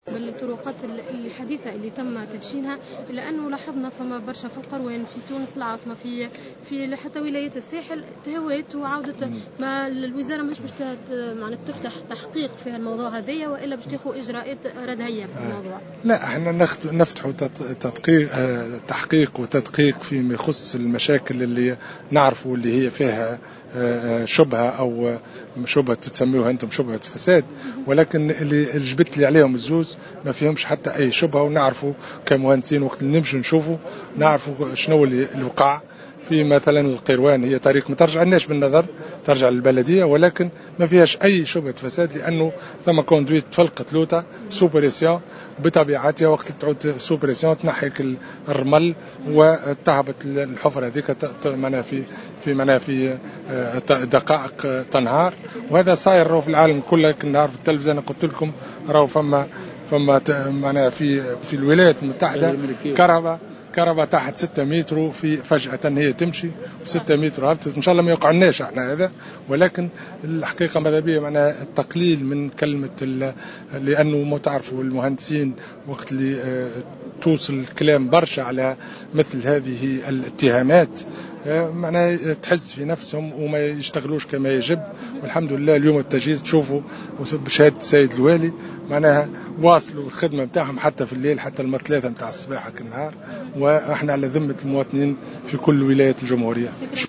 على هامش زيارة وفد حكومي للمنستير اليوم لولاية المنستير